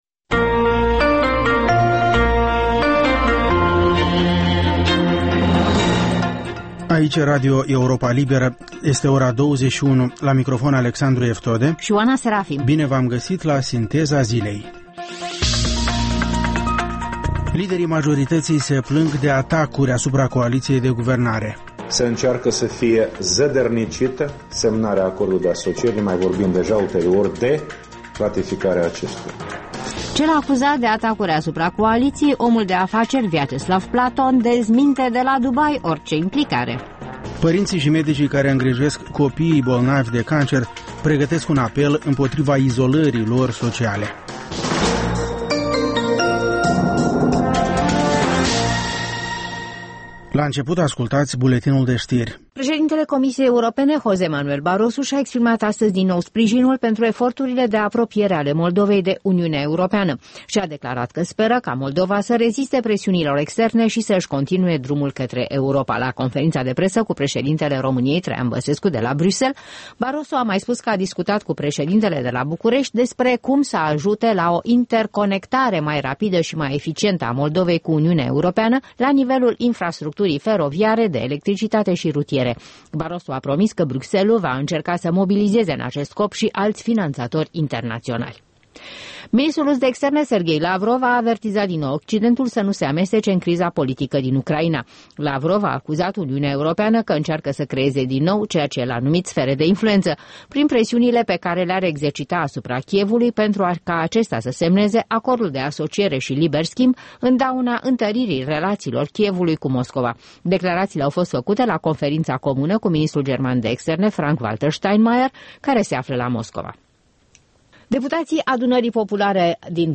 Ştiri, interviuri, analize şi comentarii.